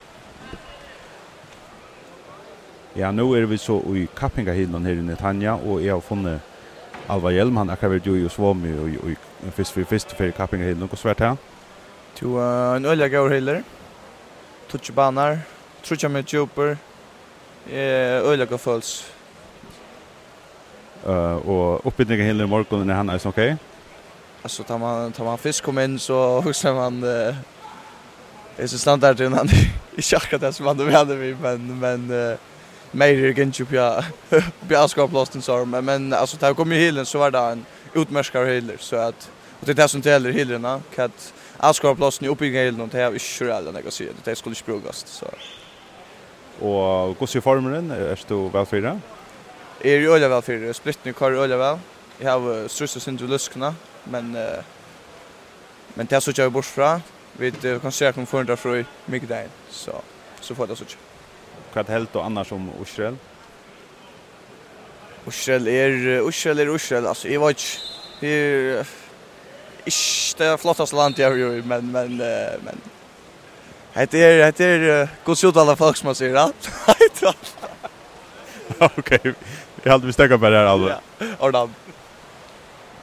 í Wingate Institute